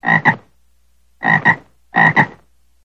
Frosch klingelton kostenlos
Kategorien: Tierstimmen